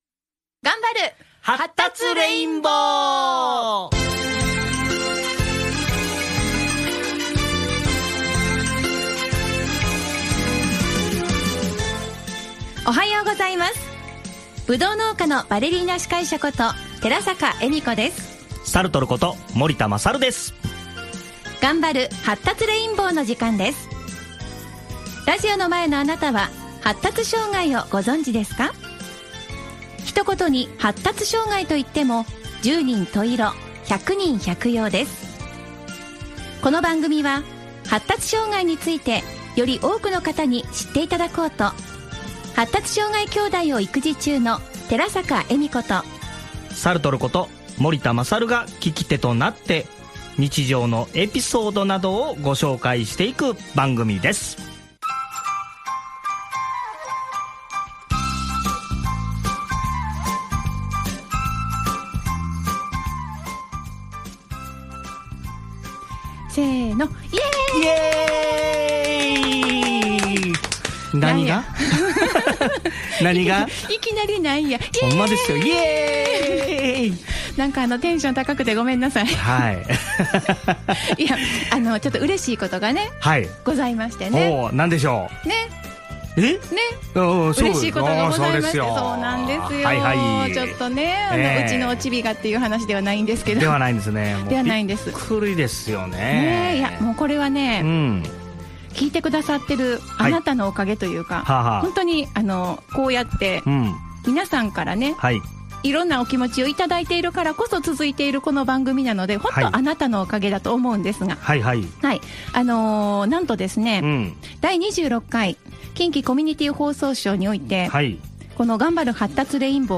聞き手役の僕の役割は、クッション材。「わからへんことをわからへん」ということで、より多くの方々に、「発達障害」について知ってもらえればと思っています。
喜びの声を伝える放送回はこちらです。